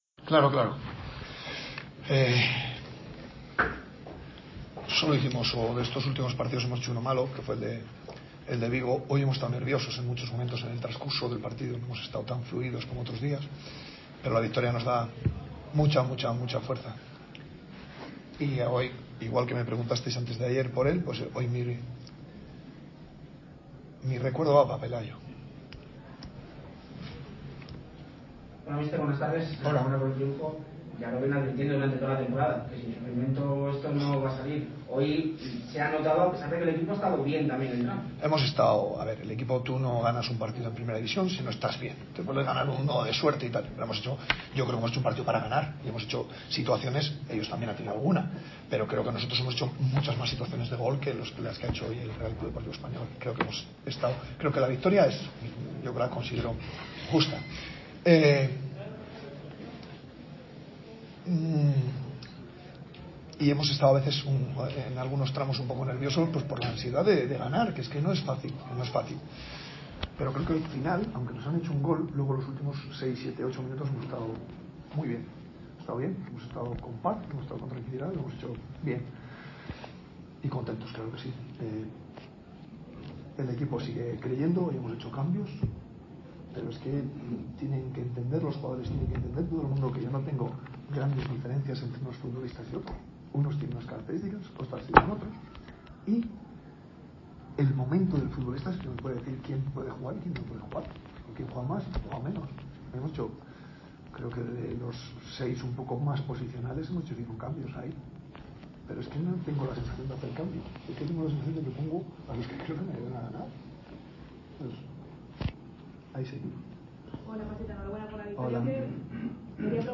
Así fue la rueda de prensa de Pacheta a la conclusión del duelo ante el RCD Espanyol en el que el Real Valladolid sumó tres puntos más en un choque apretado a la altura de esta LaLiga Santander tan igualada.